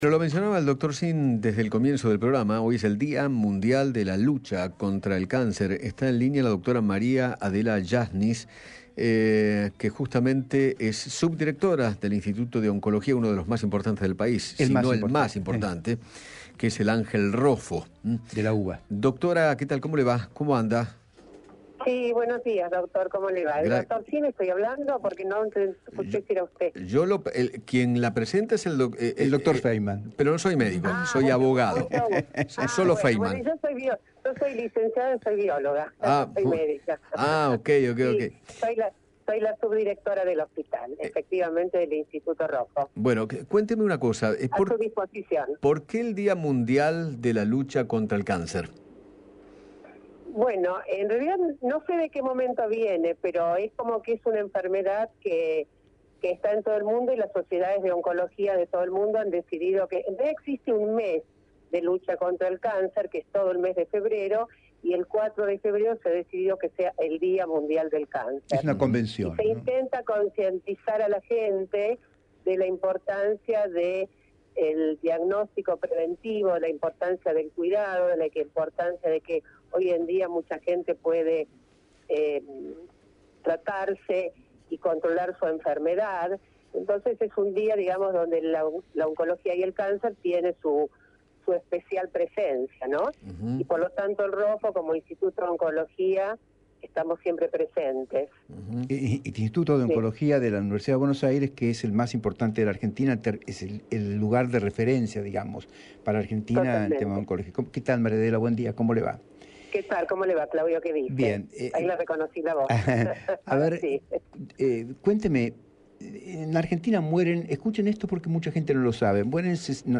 Eduardo Feinmann dialogó con